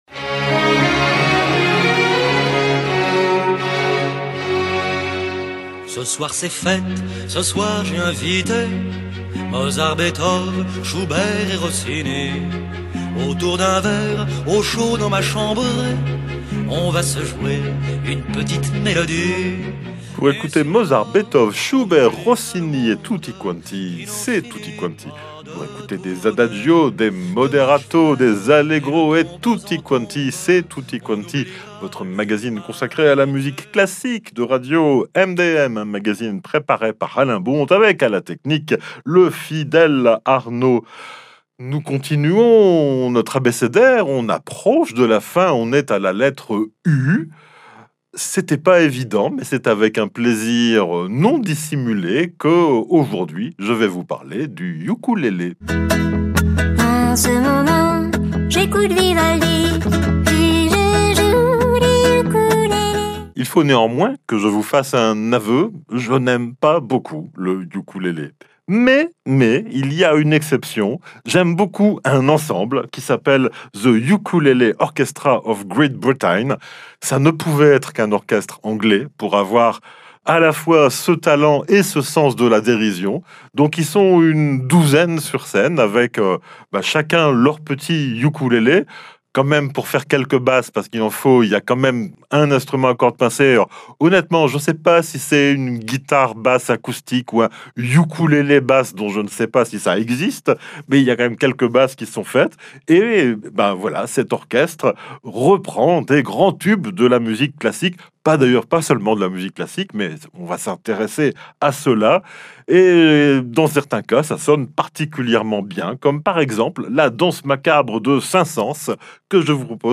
Programmation musicale éclectique, multi-générationnelle, originale, parfois curieuse, alternative et/ou consensuelle, en tous les cas résolument à l’écart des grands réseaux commerciaux.